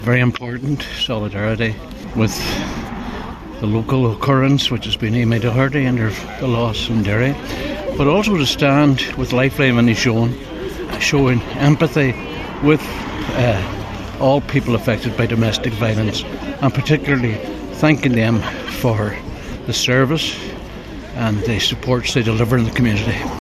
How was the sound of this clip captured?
Over 150 people gathered outside the Lifeline Inishowen premises in Carndonagh last night for a candlelit vigil